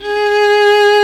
Index of /90_sSampleCDs/Roland - String Master Series/STR_Violin 1-3vb/STR_Vln3 _ marc
STR VLN3 G#3.wav